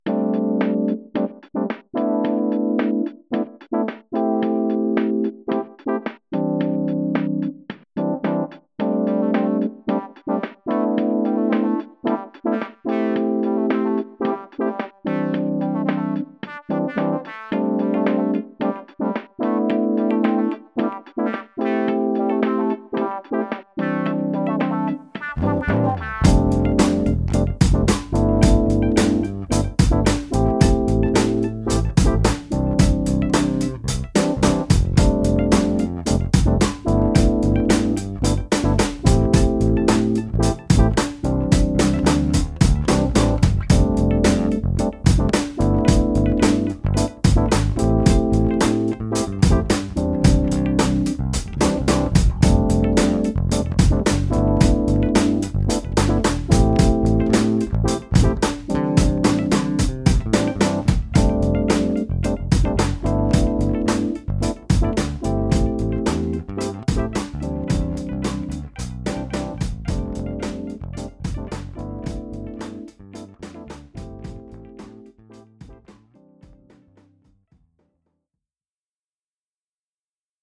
Laid back, chilled, soulful, a bit funky.